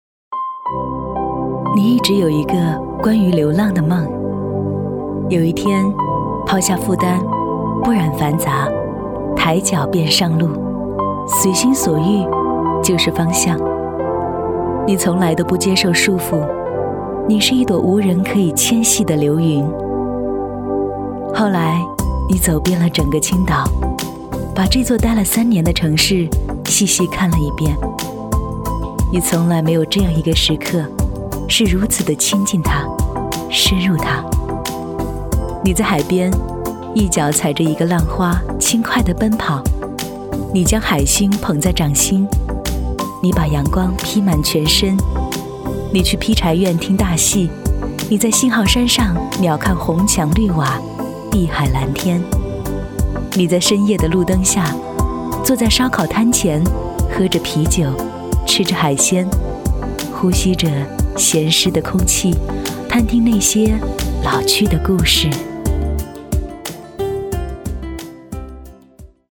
• 女S19 国语 女声 宣传片-青岛-城市宣传片-清新 自然 大气浑厚磁性|沉稳|亲切甜美